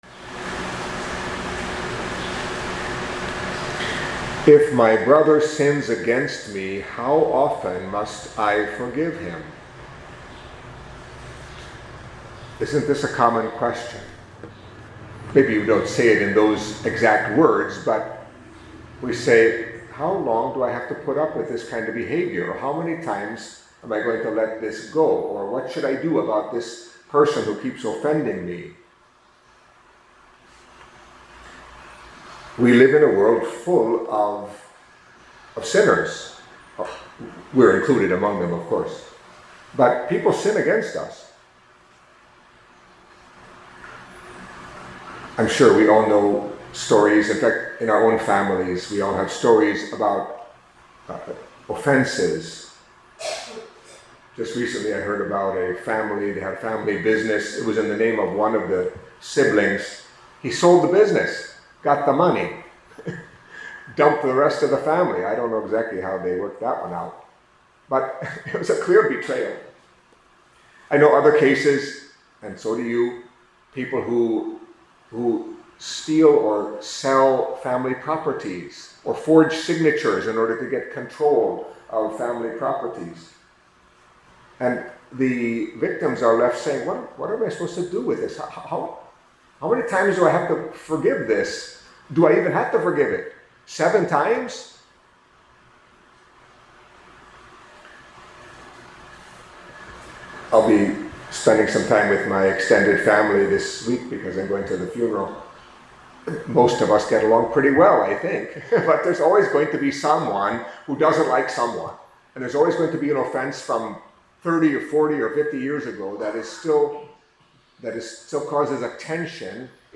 Catholic Mass homily for Tuesday of the Third Week of Lent